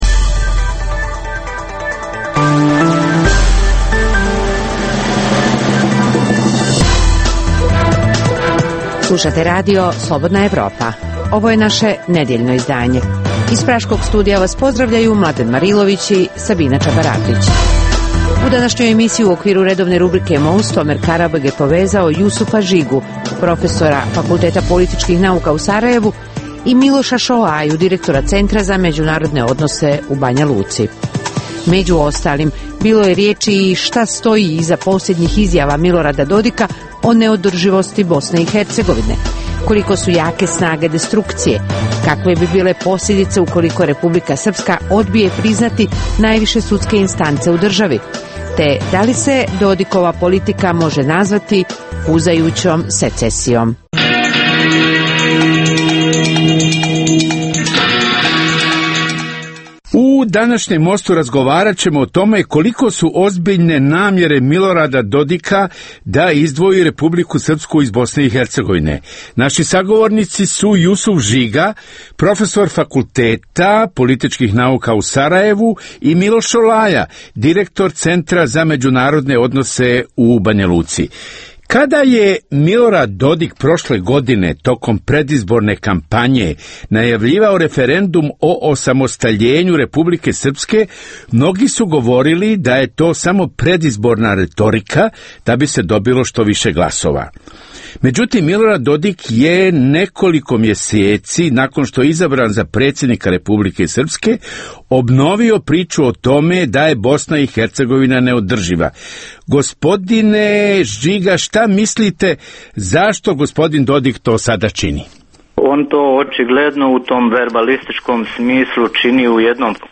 u kojem ugledni sagovornici iz regiona diskutuju o aktuelnim temama.